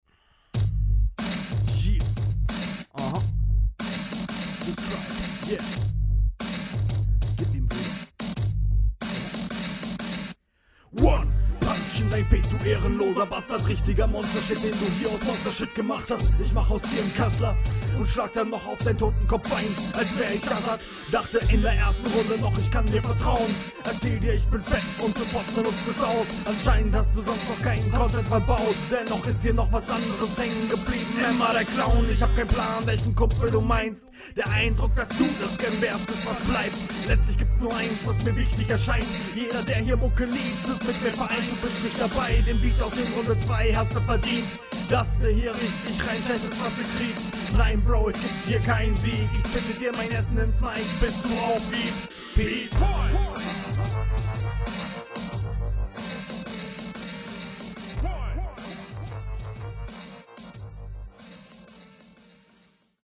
Mische ist viel zu leise. Das rauschen ist zumindest weg, was schonmal sehr positiv ist.